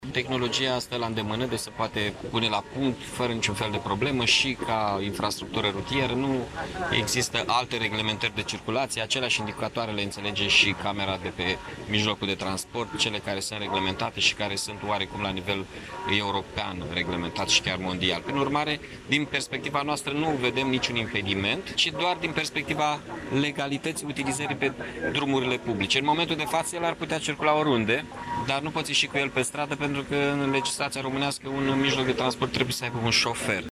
Primarul Mihai Chirica a declarat că pentru achiziţionarea şi utilizarea unui astfel de autovehicul nu este nevoie doar de resurse financiare sau de îndeplinirea unor condiţii tehnice şi de infrastructură, ci şi de o modificare legislativă care să permită circulaţia pe drumurile publice a unor astfel de vehicule inovative.